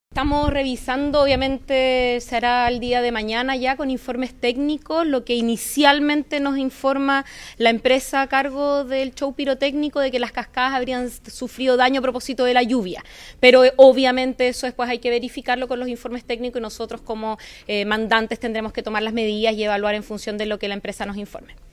El balance de la alcaldesa Carla Amtmann fue positivo, aunque confirmó que hubo problemas técnicos con una de las cascadas producto de las lluvias.